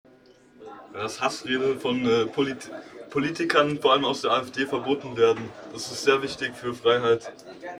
Ein Fest für die Demokratie @ Bundeskanzleramt, Berlin
Standort war das Bundeskanzleramt, Berlin.